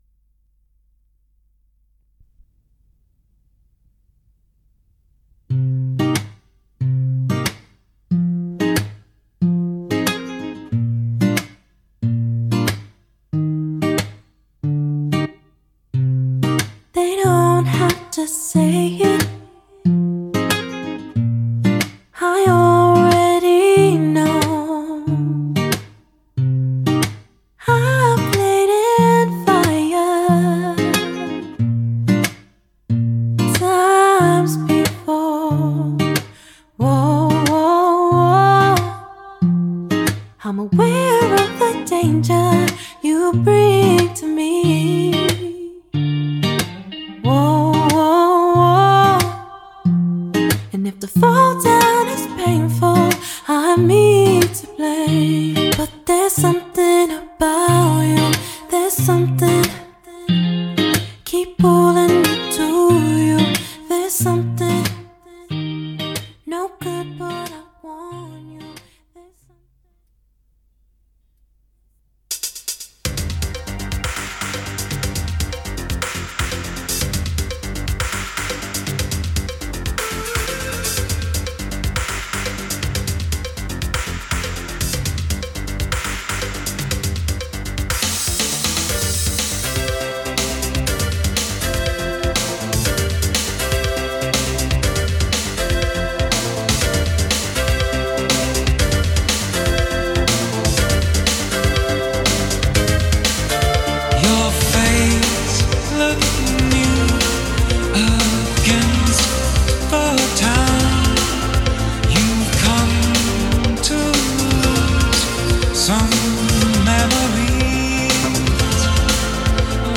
The recordings made on this deck are indistinguishable from CD playback. You can listen below to one yourself, with two tracks recording with Dolby C and the RS-B965. The tape used is a blank TDK SA-90 Type II.